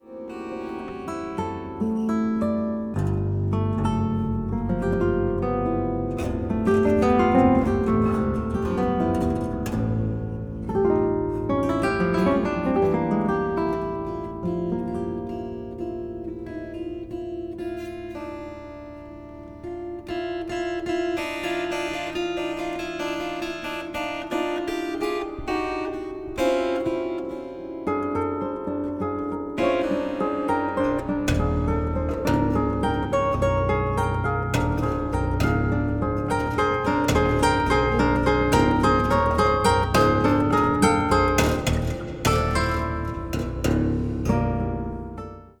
16-string classical guitar, 16-string Contraguitar